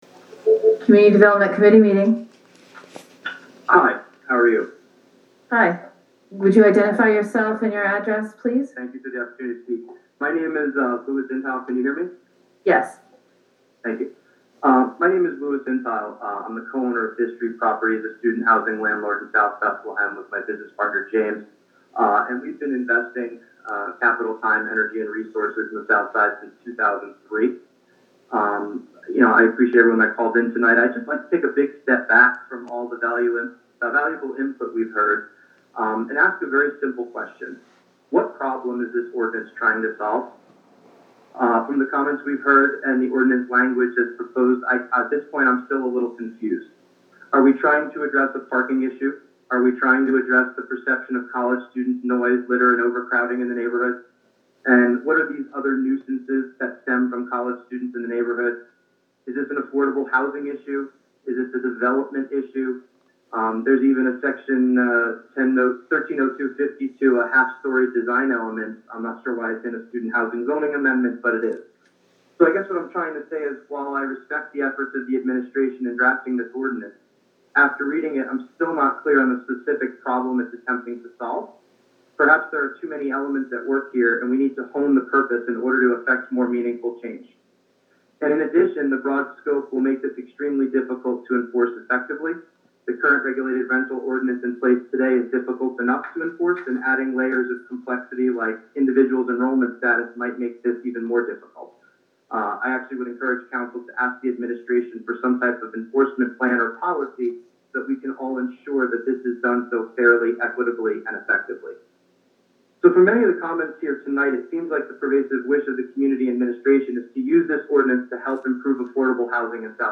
About 20 residents commented at the meeting, the overwhelming majority in favor of the ordinance.
Southside landlord (2) (7 mins.)